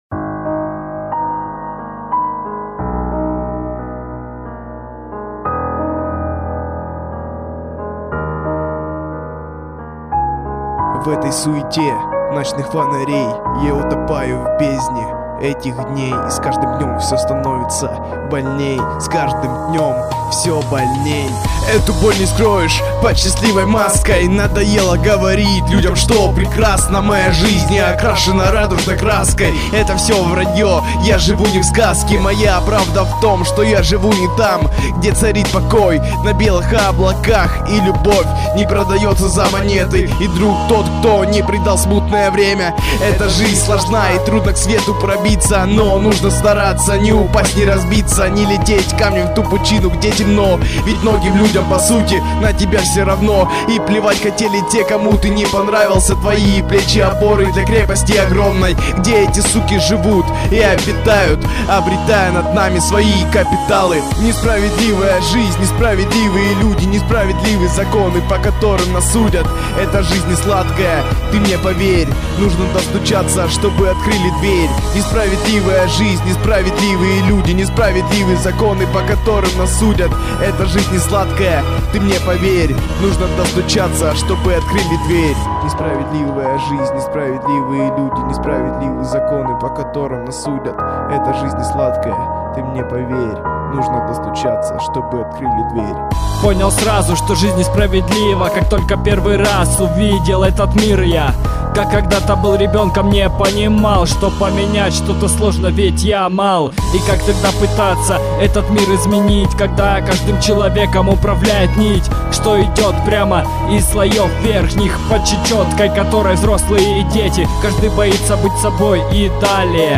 Rap & Hip-hop